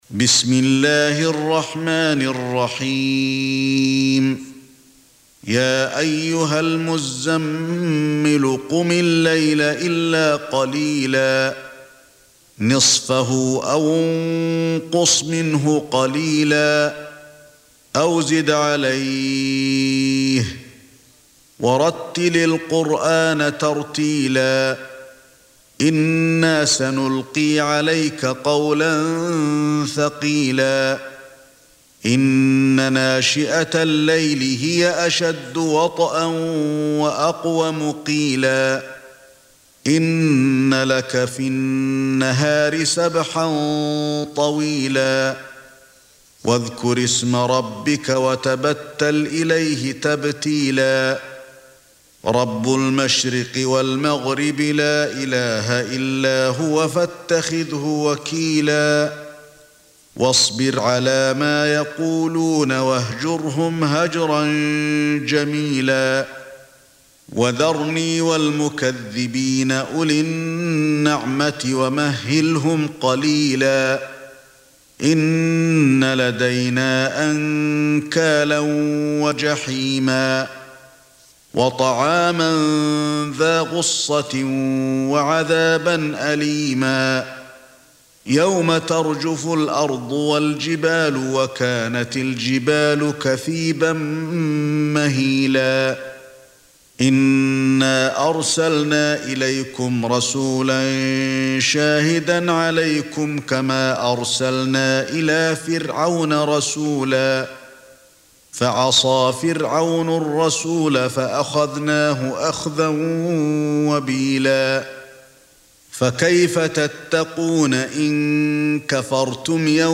Surah Sequence تتابع السورة Download Surah حمّل السورة Reciting Murattalah Audio for 73. Surah Al-Muzzammil سورة المزّمّل N.B *Surah Includes Al-Basmalah Reciters Sequents تتابع التلاوات Reciters Repeats تكرار التلاوات